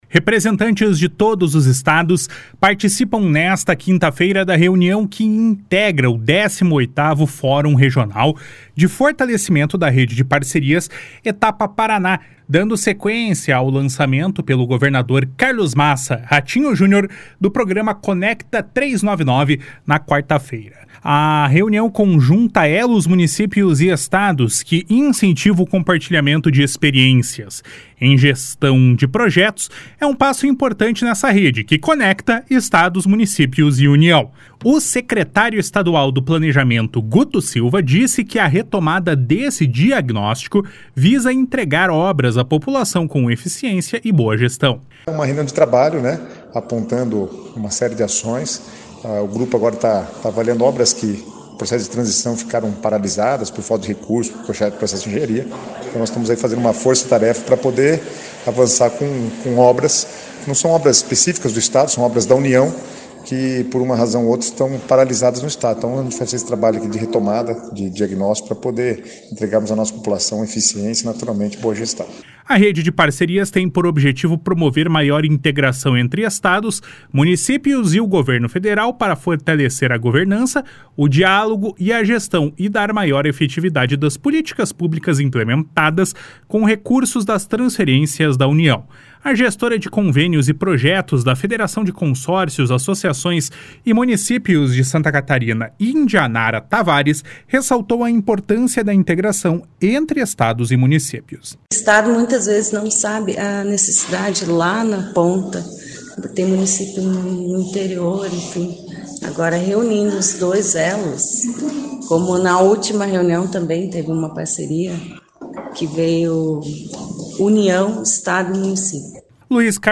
O secretário estadual do Planejamento, Guto Silva, disse que a retomada desse diagnóstico visa entregar obras à população com eficiência e boa gestão. // SONORA GUTO SILVA //